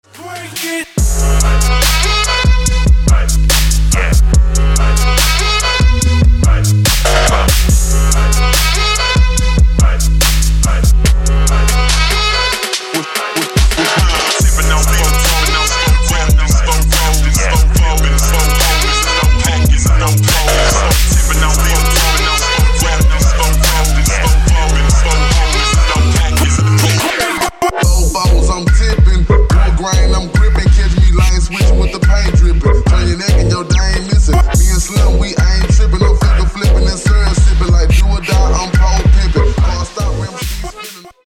• Качество: 192, Stereo
Хип-хоп
Trap
Rap
Bass